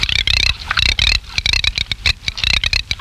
Sarcelle d'été
anas querquedula
sarcelle_e.mp3